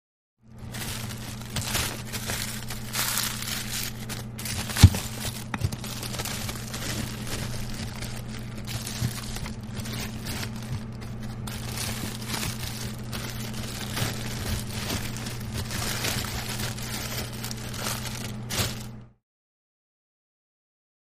Freezer; Interior Movement; Food Wrapped In Foil And Plastic Movement With Interior Freezer Hum In Background. Close Perspective. Kitchen.